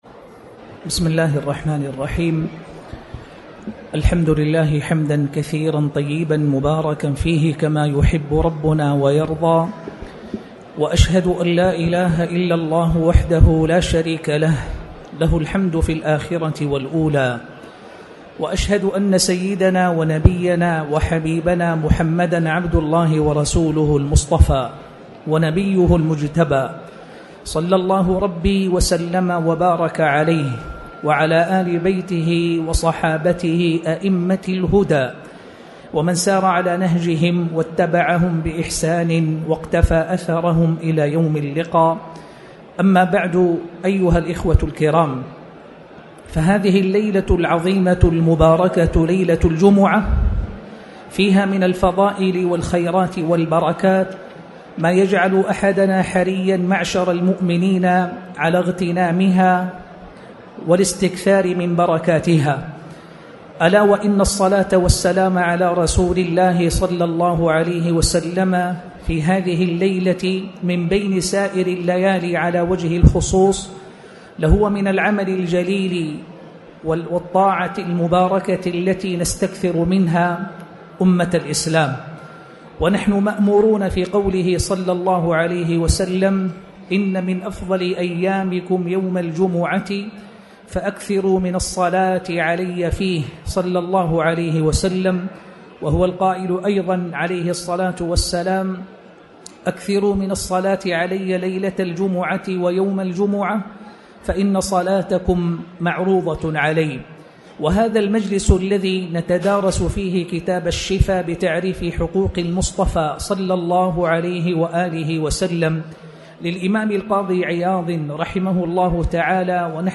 تاريخ النشر ٢٧ ذو القعدة ١٤٣٩ هـ المكان: المسجد الحرام الشيخ